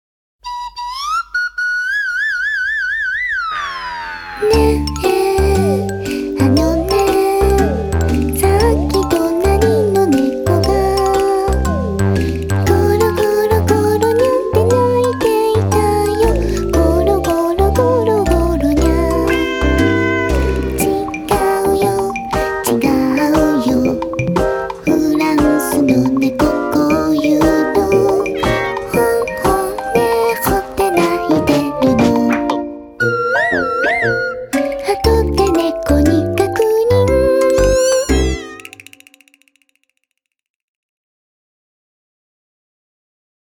楽しくて可愛くてヘッポコで、ちょっぴり切ない。
POP